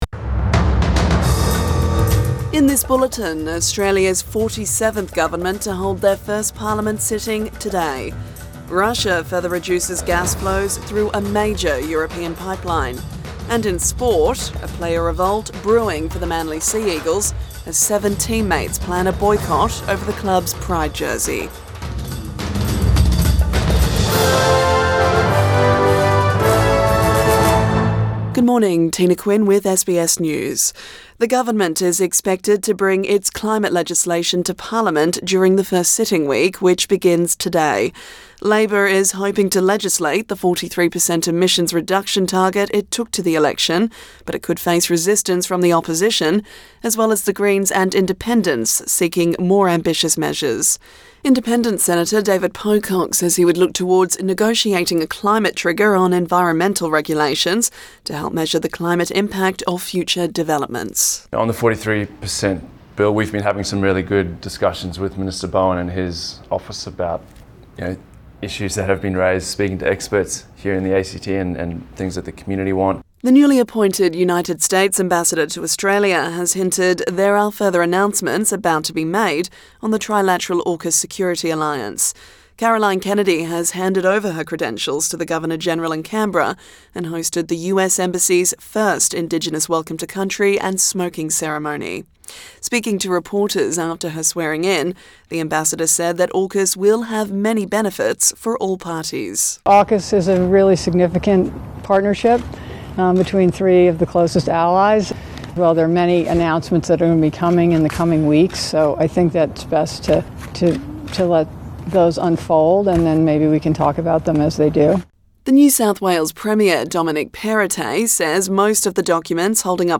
AM bulletin 26 July 2022